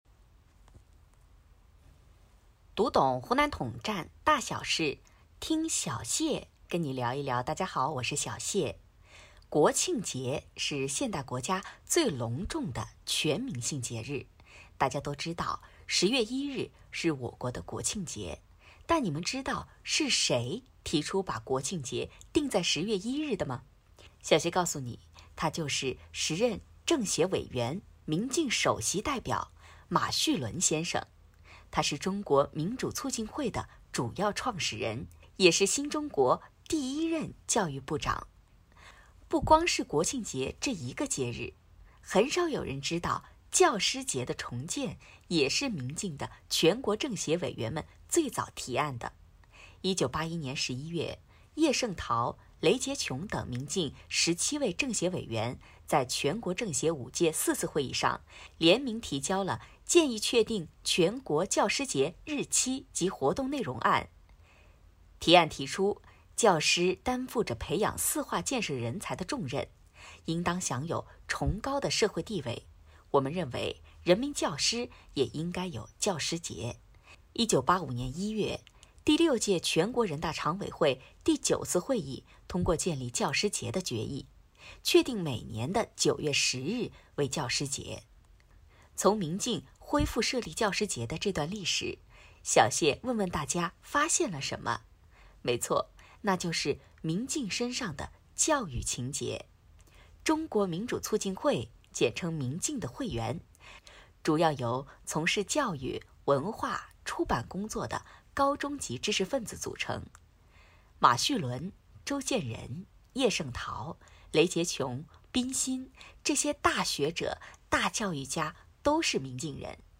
栏目主播采用更生活化、更口语化、更亲切的播音路子，能让统战知识以一种更加有亲和力的方式传播，增强了统战宣传的生动性和感染力，让栏目办出自己的特色与风格，能够在广大统战成员和更广泛的读者群中都产生了较好的影响,从而用创新方式打造一款统战宣传精品。